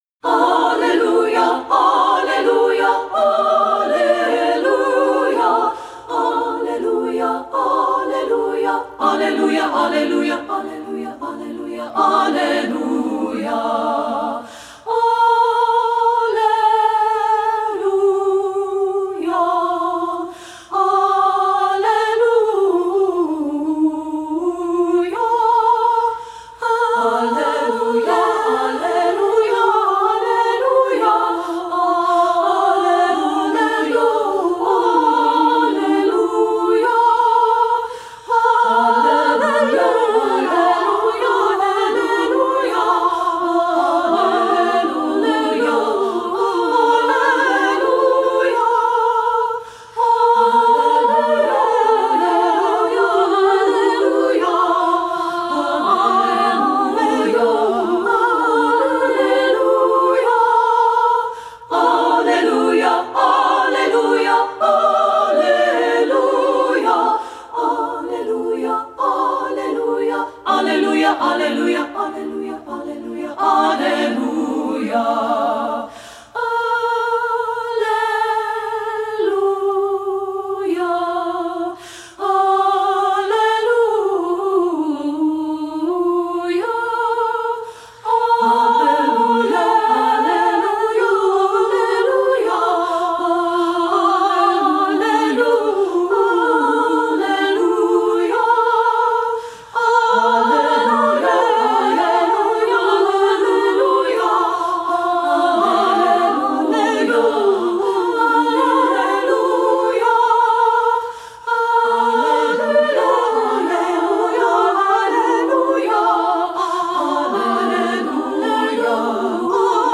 • Soprano 1
• Soprano 2
• Alto
• Piano (opt.)
Studio Recording
This classic canon for treble voices
Ensemble: Treble Chorus
Key: B major
Tempo: Maestoso (h = 82)
Accompanied: Accompanied Chorus